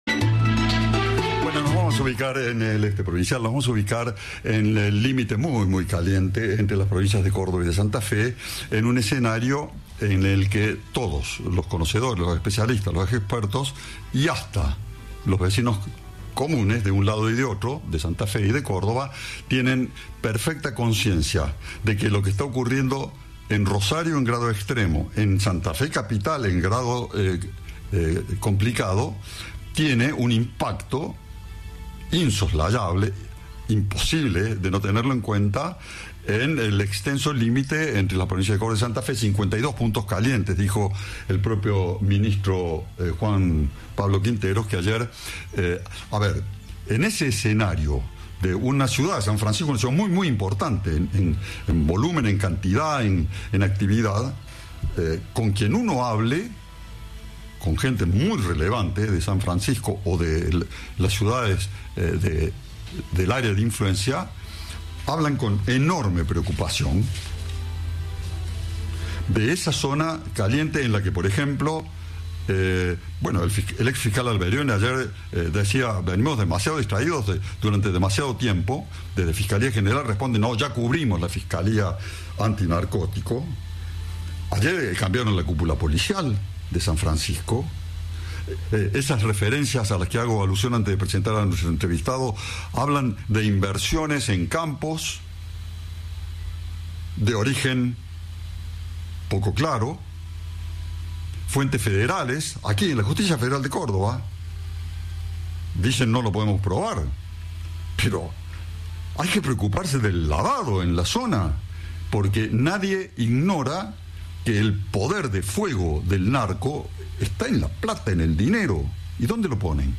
El obispo de San Francisco habló con Cadena 3 sobre las amenazas narco a policías locales y de la escalada de violencia en Rosario.
Entrevista